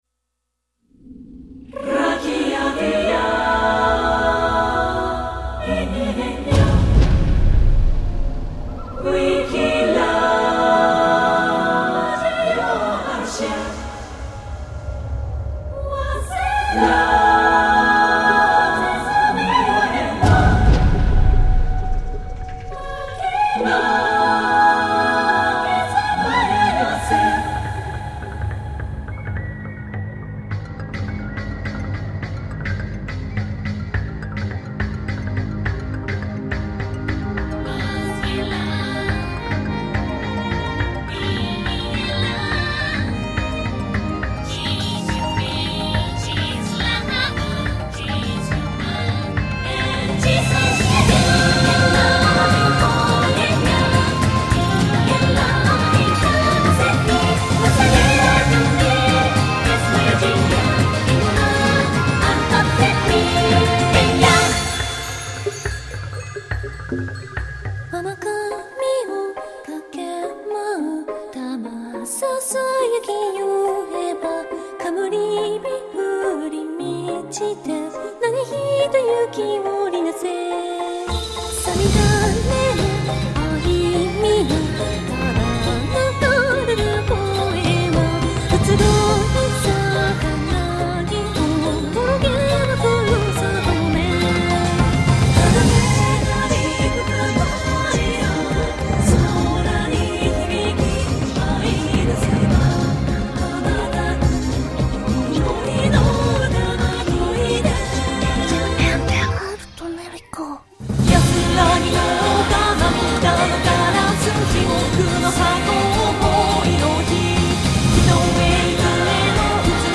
結構ビートがきいている部分があったり、電子系の音 が多めに入っていますが
前代未聞の超コーラス曲が完成し